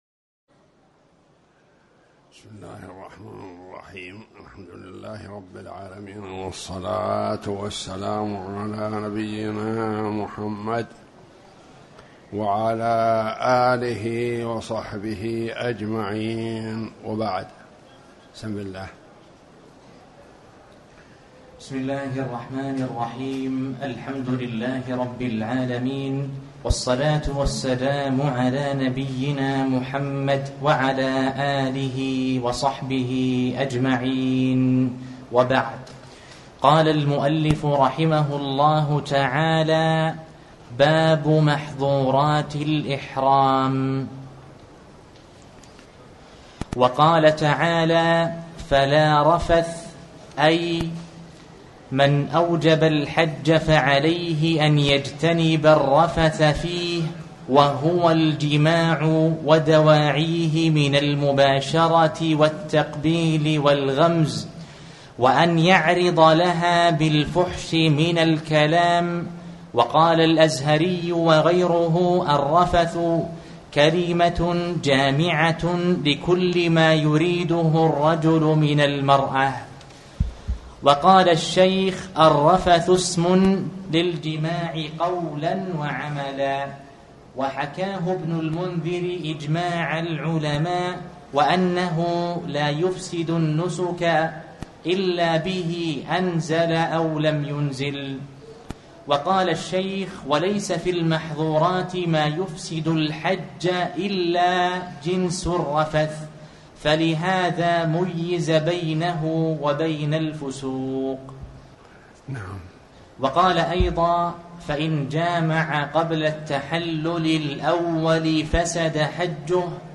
تاريخ النشر ٥ محرم ١٤٤٠ هـ المكان: المسجد الحرام الشيخ